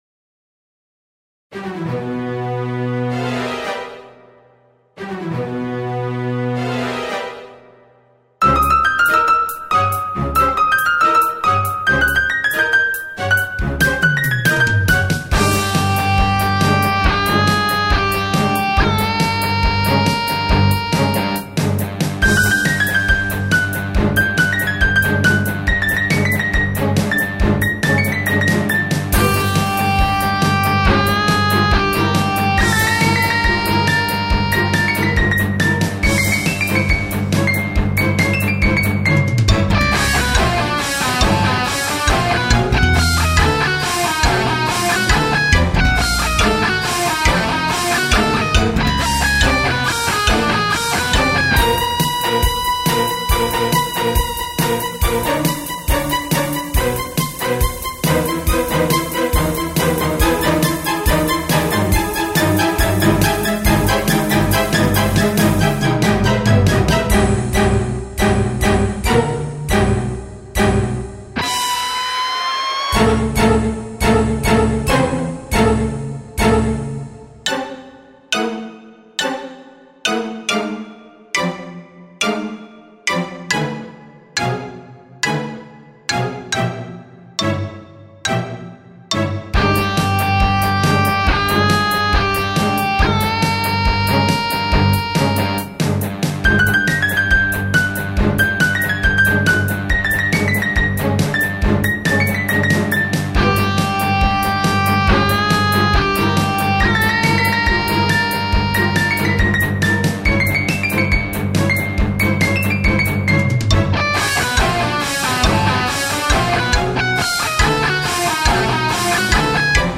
Зная теперь Вашу любовь к симфо-року, выложил Битву Ланселота с Драконом.